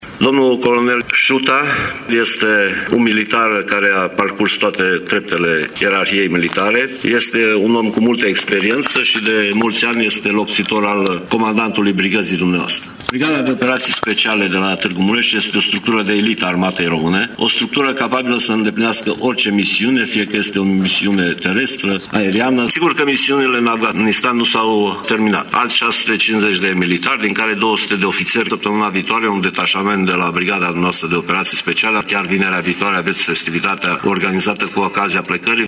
Ministrul Apărării Naționale, Mircea Dușa: